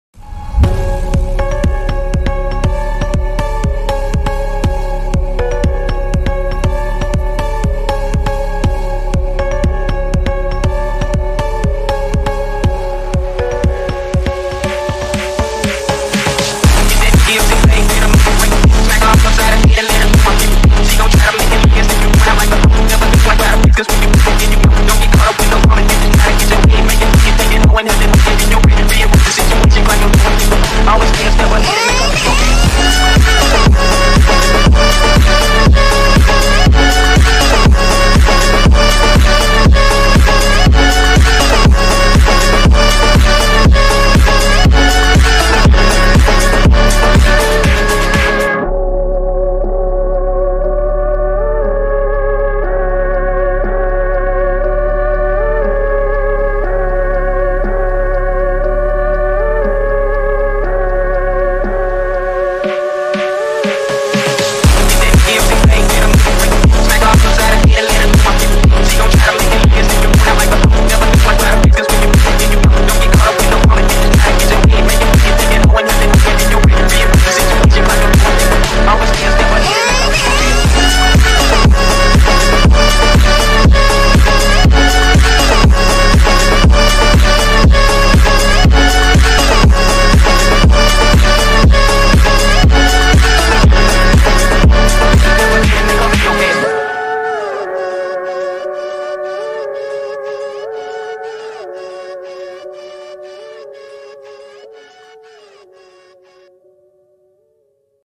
Baby Crying Phonk full version.mp3